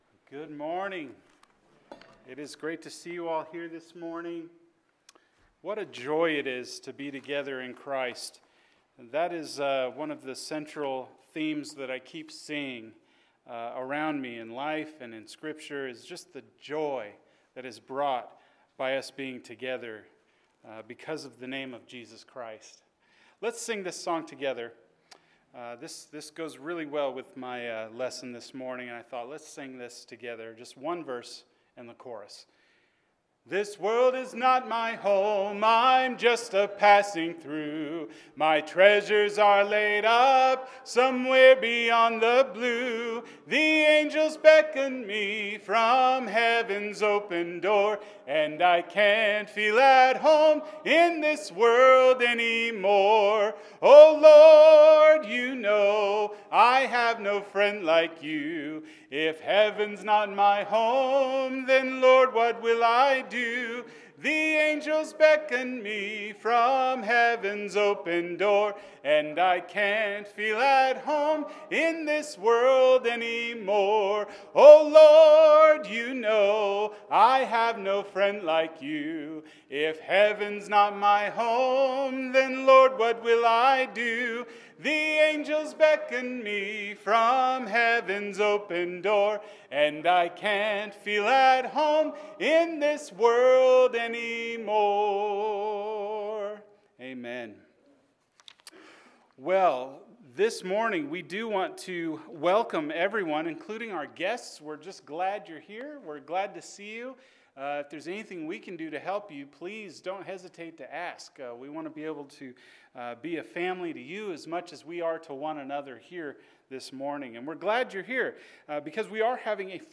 The Cross Has Enemies – Philippians 3:17-4:1 – Sermon